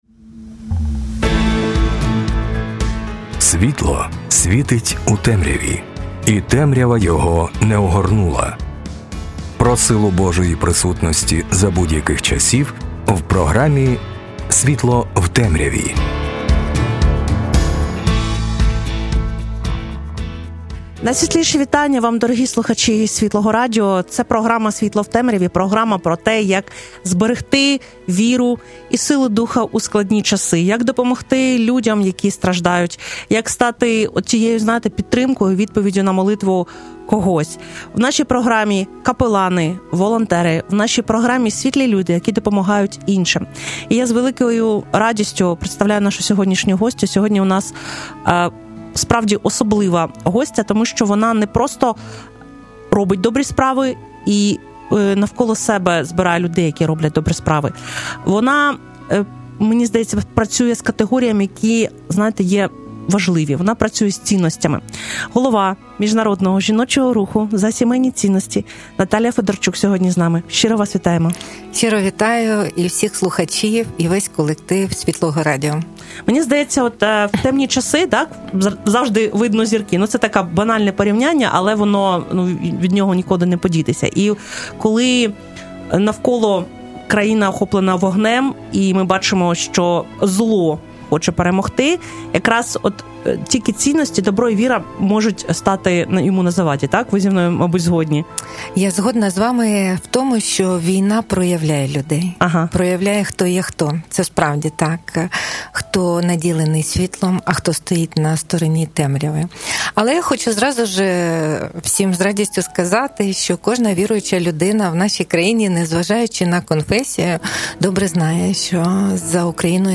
Щира розмова про національні молитовні сніданки та інші культурні, духовні та ціннісні заходи, що допомагають підтримати українську жінку, сімʼю та країну.